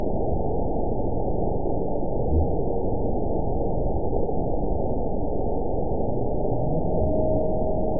event 921793 date 12/19/24 time 02:23:28 GMT (4 months, 2 weeks ago) score 7.10 location TSS-AB02 detected by nrw target species NRW annotations +NRW Spectrogram: Frequency (kHz) vs. Time (s) audio not available .wav